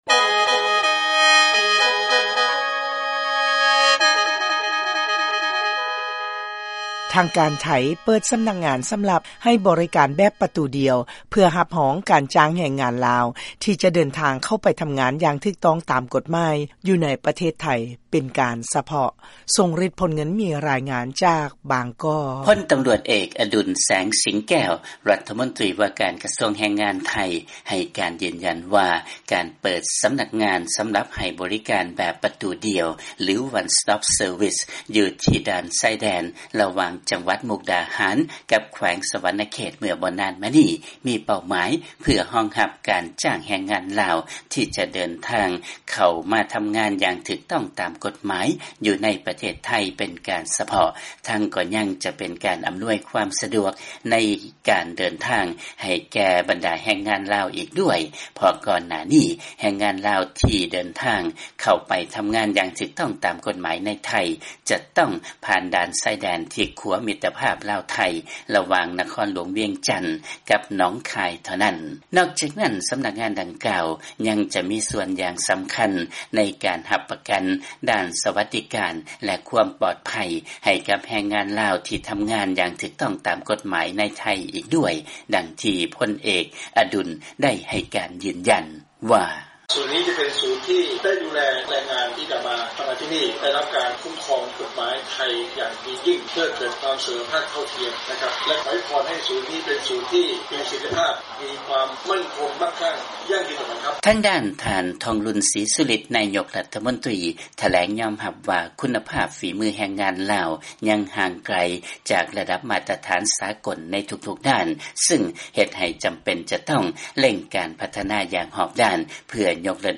ມີລາຍງານຈາກບາງກອກ.